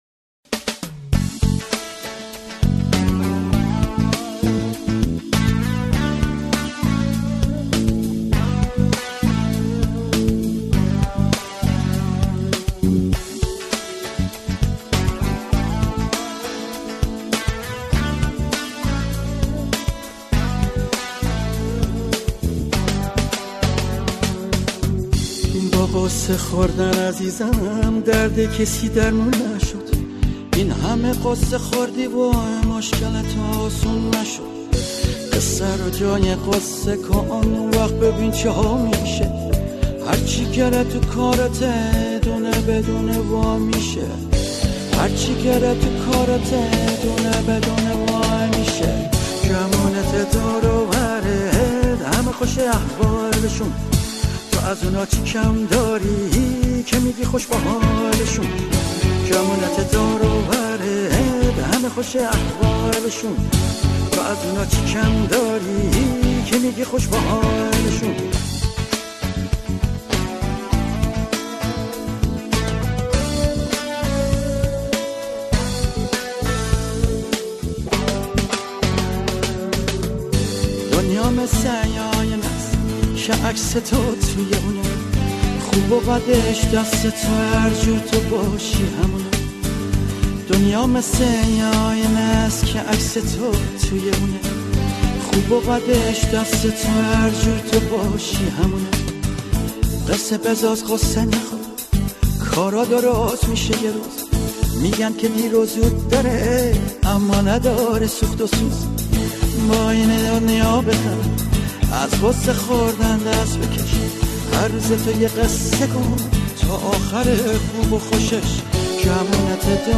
گیتار ۱۲ سیمی
آهنگسازی متأثر از موسیقی راک غربی
موسیقی پاپ ایران